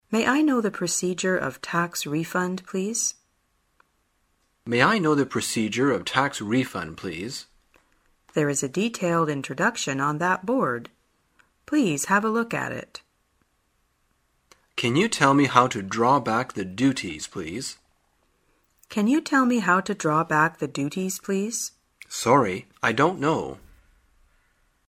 旅游口语情景对话 第268天:如何询问办理退税的程序